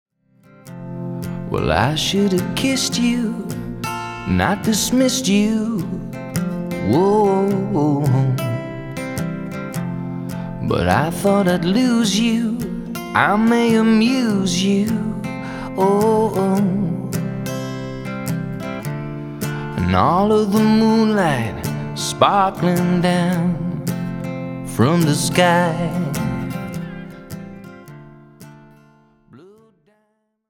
Tipps Reverb für akustische Gitarre
Anhänge Bildschirmfoto 2019-01-30 um 10.15.02.png 62,6 KB · Aufrufe: 262 reverb_test.mp3 1,2 MB · Aufrufe: 234 Bildschirmfoto 2019-01-30 um 10.16.15.png 1,5 MB · Aufrufe: 250